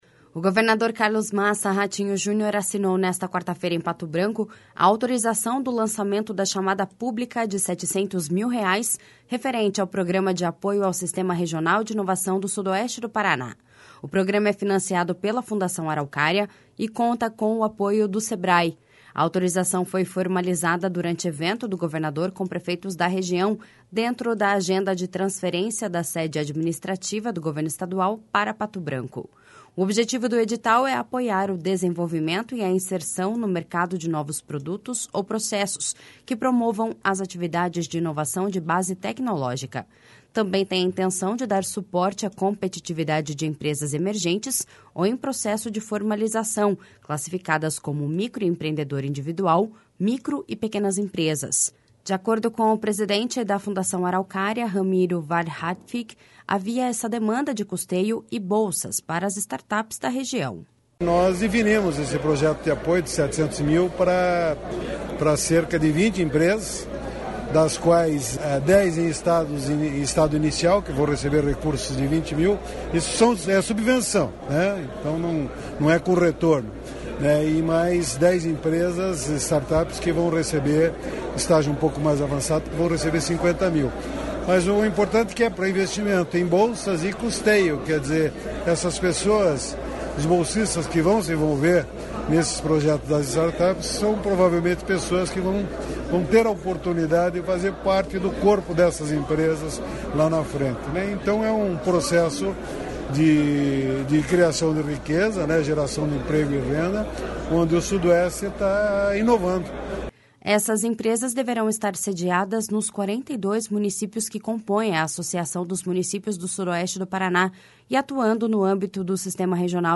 De acordo com o presidente da Fundação Araucária, Ramiro Wahrhaftig, havia essa demanda de custeio e bolsas para as startups da região.// SONORA RAMIRO WAHRHAFTIG.//
Segundo Henrique Domakoski, superintendente de Inovação da Casa Civil, o edital foi construído em parceria com a governança local.// SONORA HENRIQUE DOMAKOSKI.//